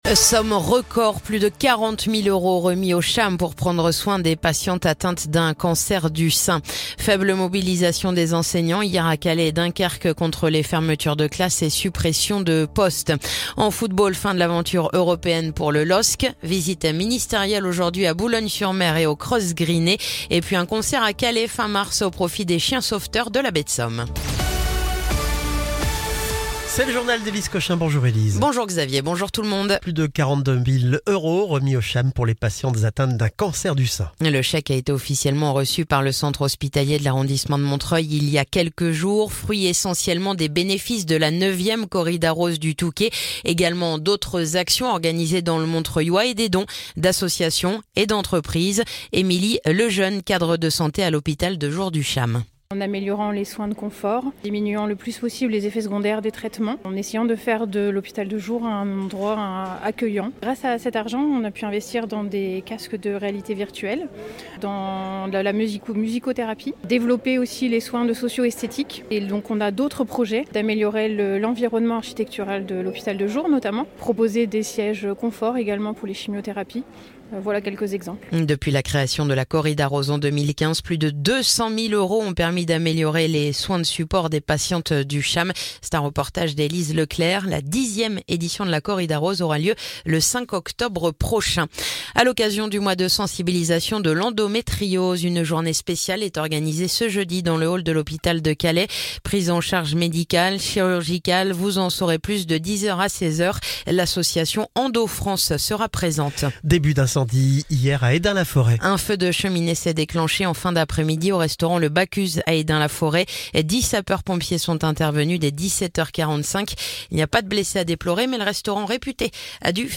Le journal du jeudi 13 mars